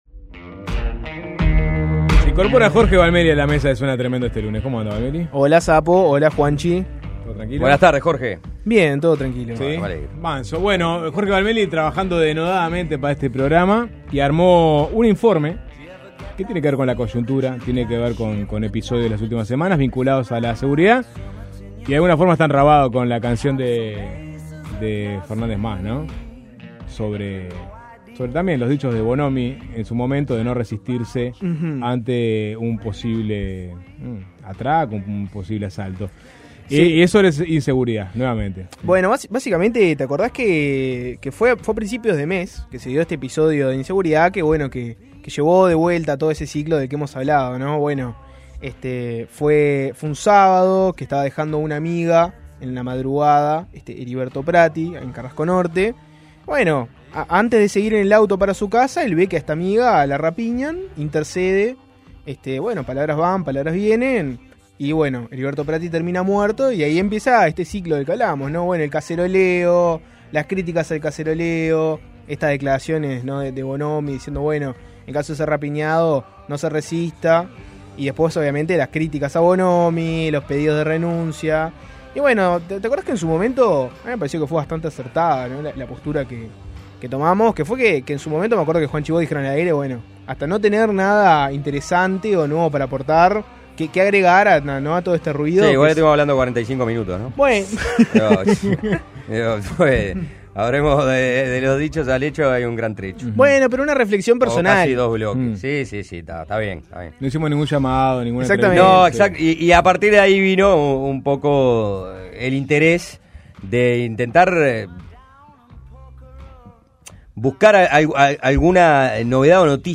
Sobre este tema hablamos con dos abogados que realizaron juicios al Estado, uno exitoso y otro no.